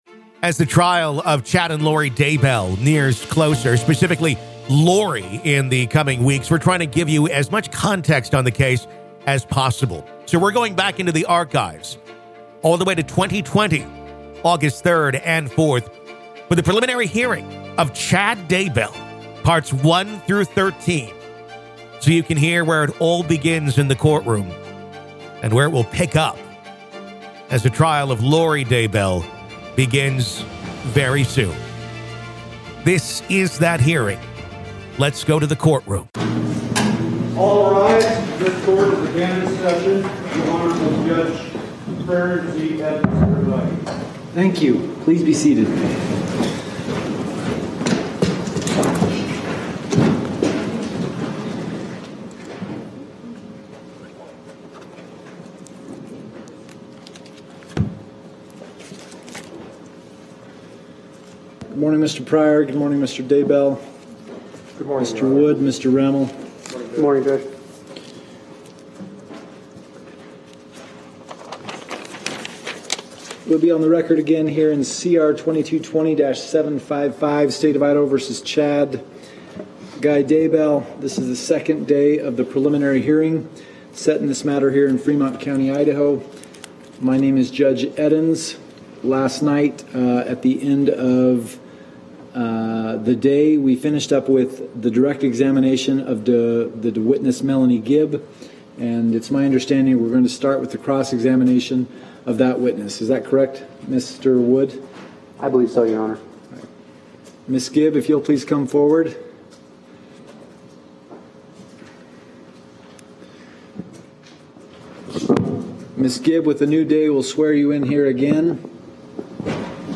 Listen To The Full Preliminary Hearing Of Chad Daybell, Part 9